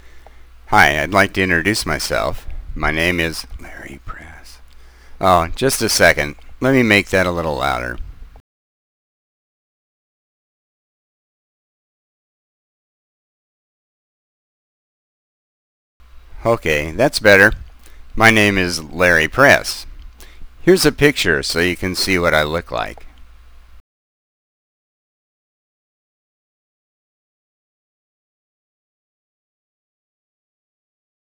I recorded a 31-second screen cast introducing myself.